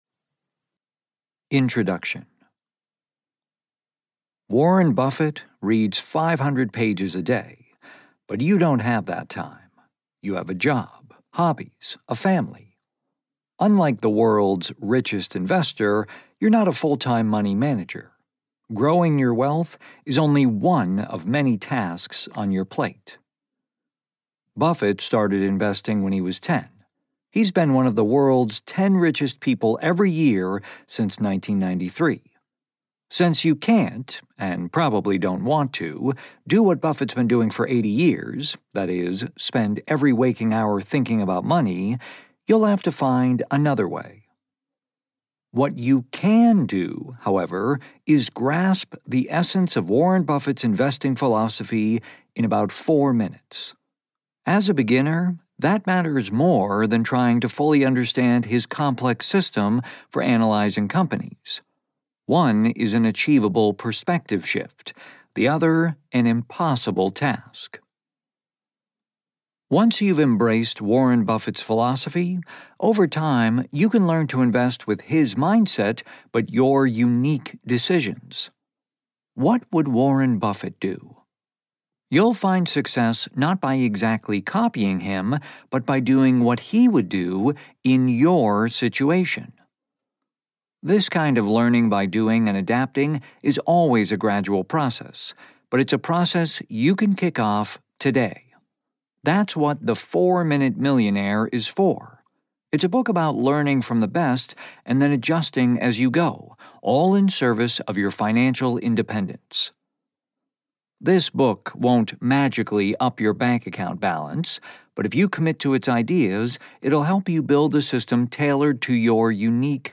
Audio knihaThe 4 Minute Millionaire: 44 Lessons
Ukázka z knihy